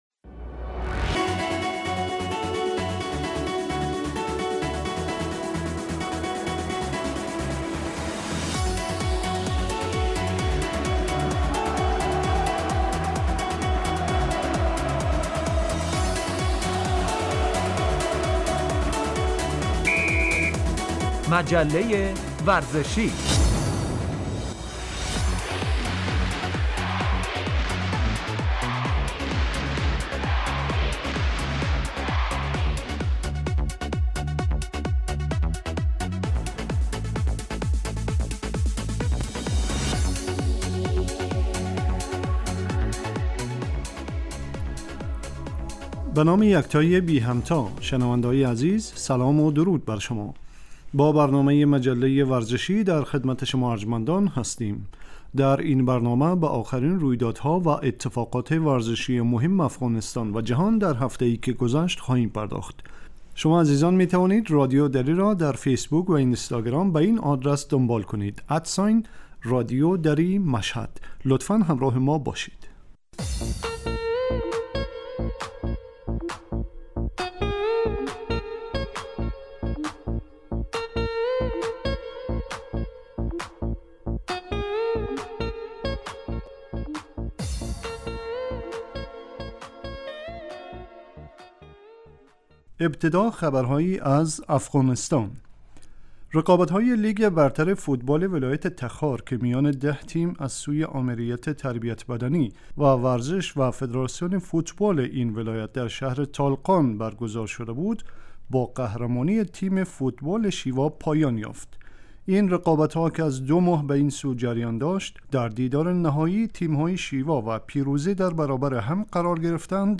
آخرین رویدادهای ورزشی ایران افغانستان و جهان درهفته گذشته به همراه گزارش و مصاحبه